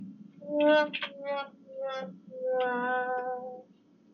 sad_trombone.mp3